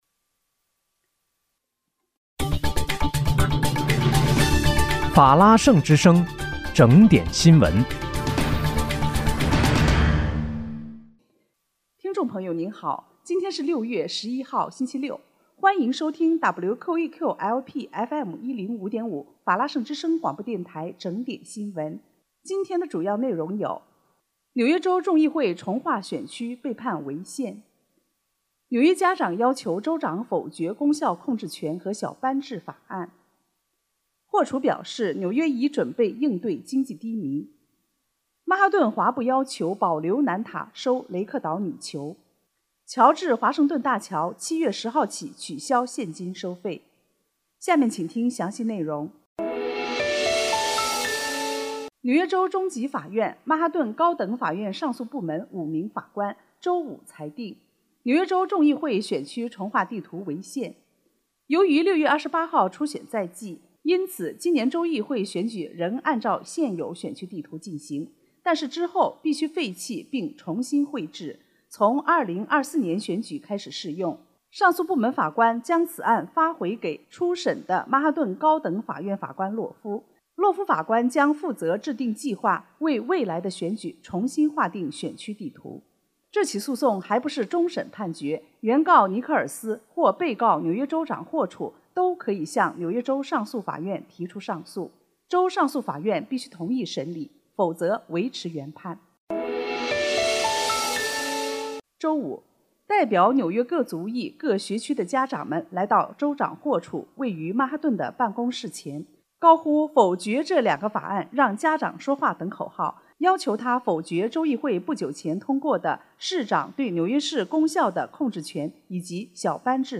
6月11日（星期六）纽约整点新闻
听众朋友您好！今天是6月11号，星期六，欢迎收听WQEQ-LP FM105.5法拉盛之声广播电台整点新闻。